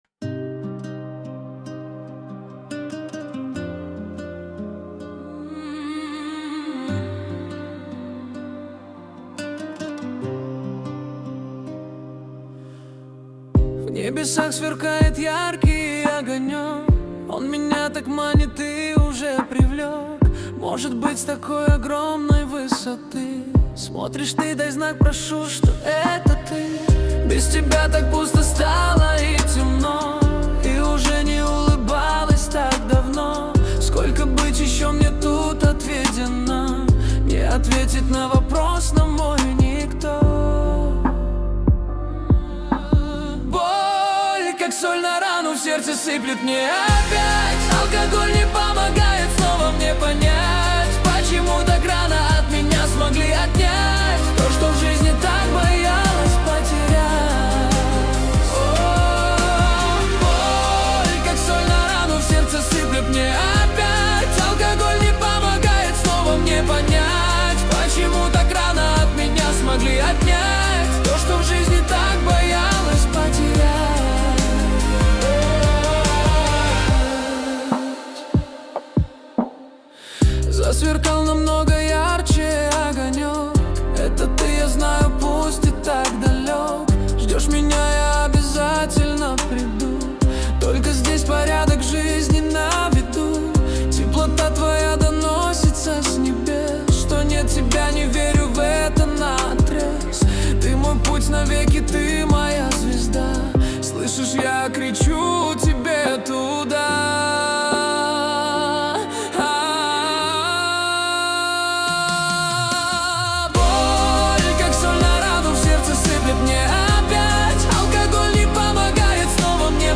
Качество: 320 kbps, stereo
Стихи, Нейросеть Песни 2025